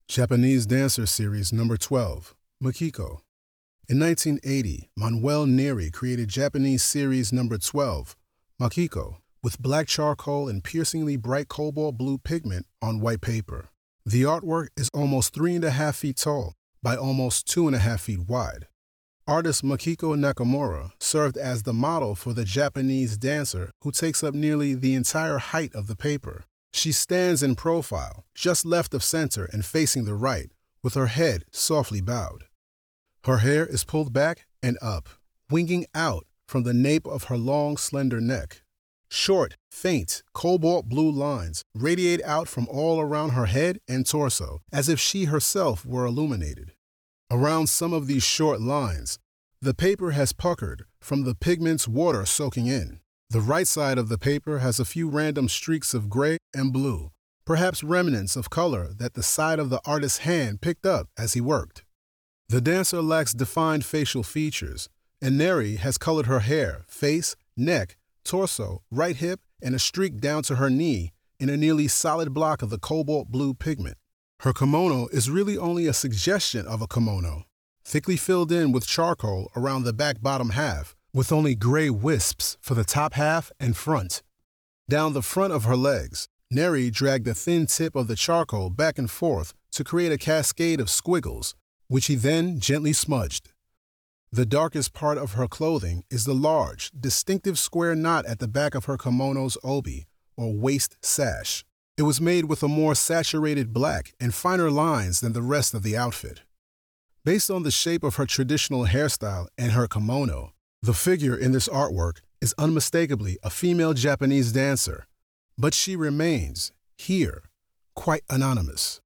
Audio Description (01:59)